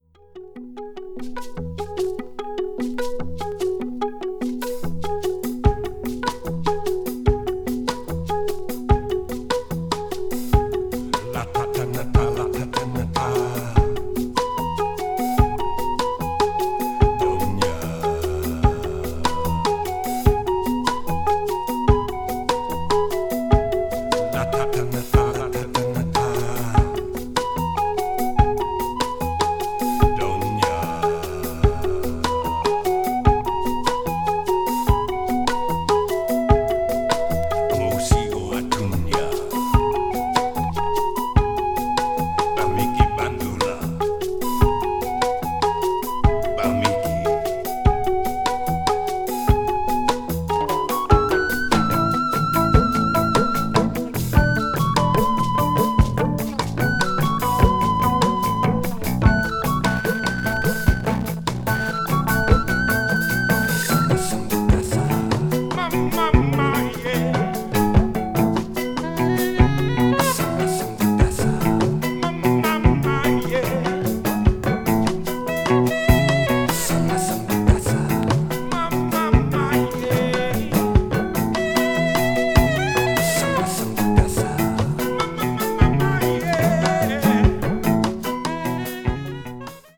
crossover   jazz rock   new age   progressive rock